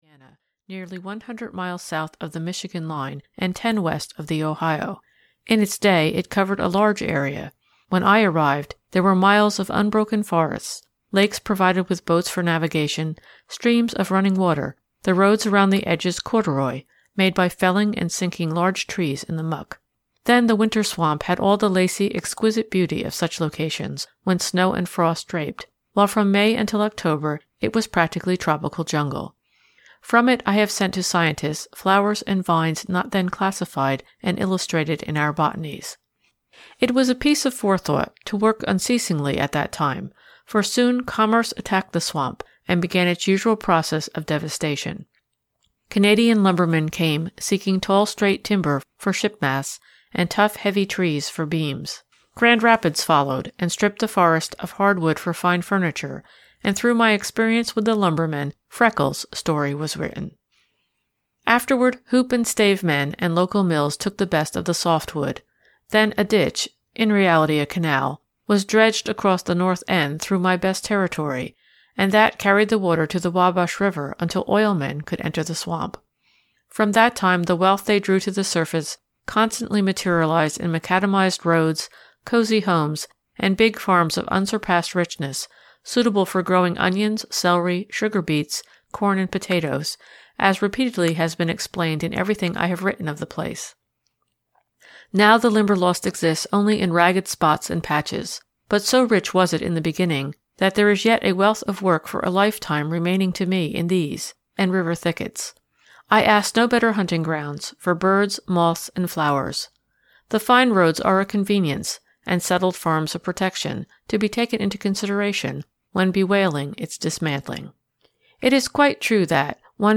Moths of the Limberlost (EN) audiokniha
Ukázka z knihy